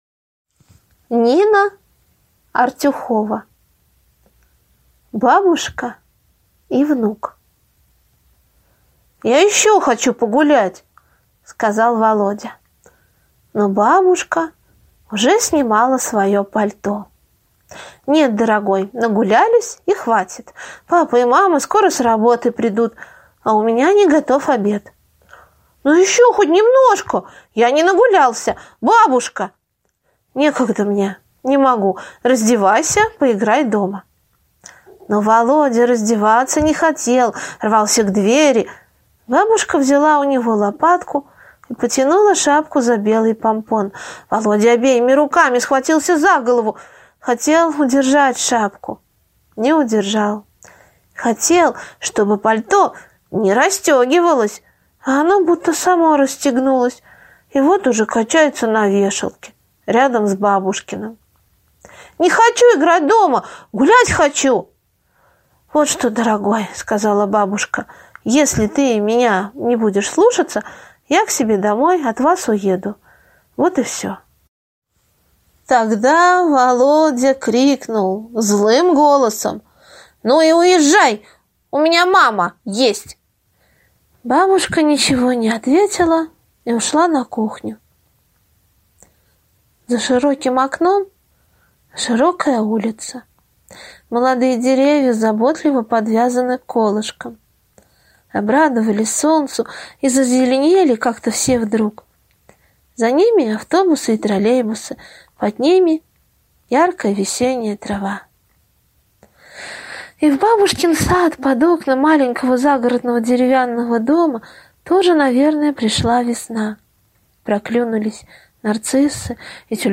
На данной странице вы можете слушать онлайн бесплатно и скачать аудиокнигу "Бабушка и внук" писателя Нина Артюхова.